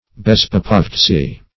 Bezpopovtsy \Bez`po*pov"tsy\, n. [Russ.; bez without + popovtsy,